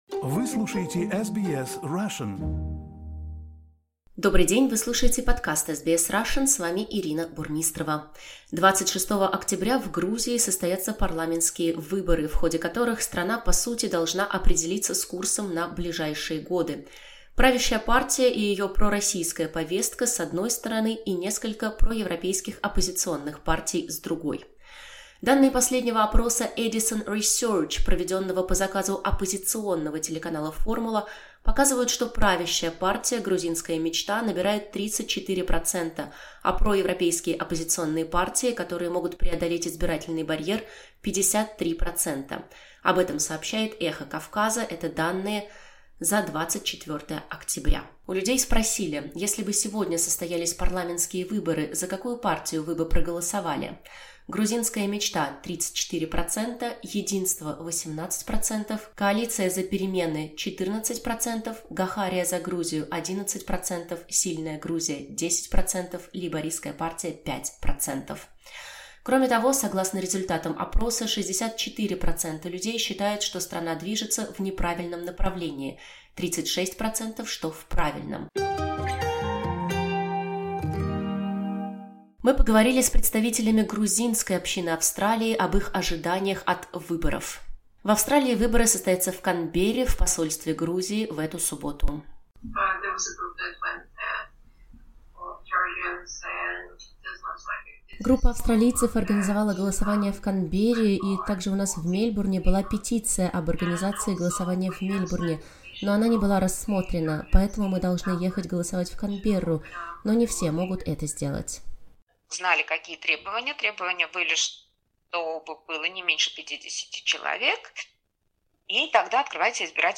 Мы поговорили с представителями грузинской общины Австралии об их ожиданиях от выборов. В Австралии выборы состоятся в Канберре в посольстве Грузии в эту субботу.